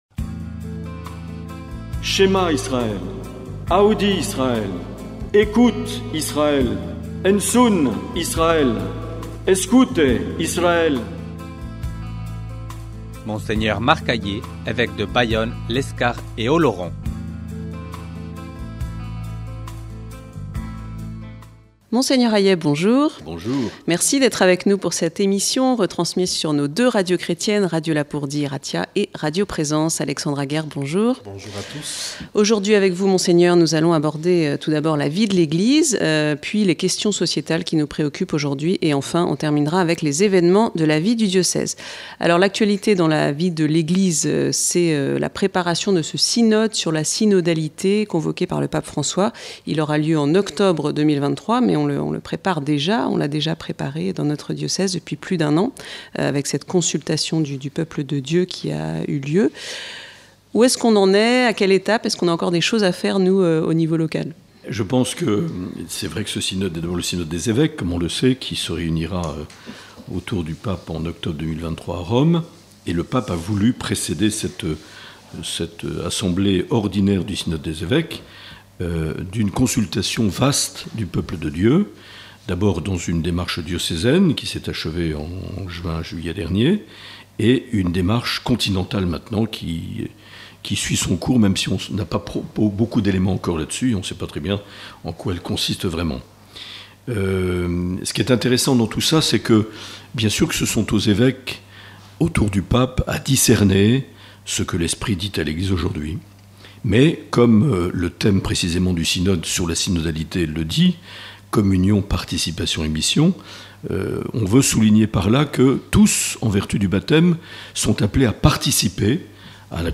Sujets abordés lors de cet entretien enregistré le 20 septembre 2022 pour Radio Lapurdi Irratia et Radio Présence Lourdes-Pyrénées : le synode sur la synodalité ; la loi sur la fin de vie ; l’interpellation de migrants à l’église d’Urrugne ; évènements de la vie diocésaine.